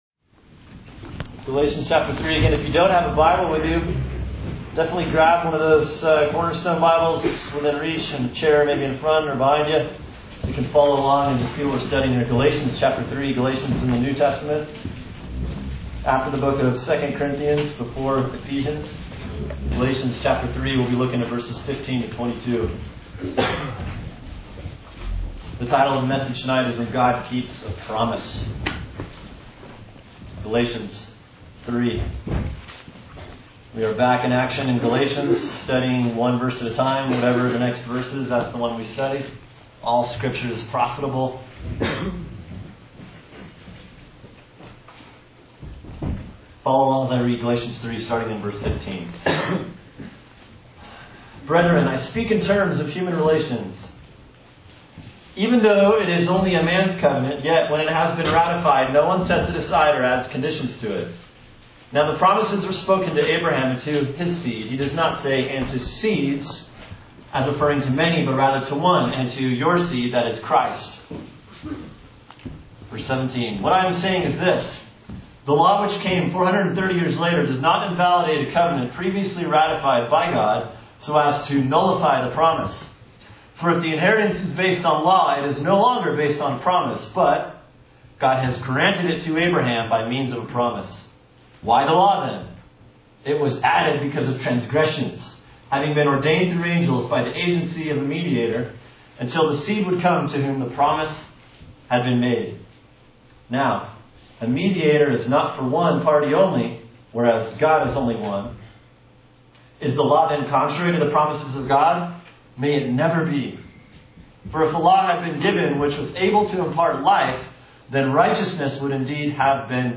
Sermon: Galatians 3:15-22 “When God Keeps a Promise” | Cornerstone Church - Jackson Hole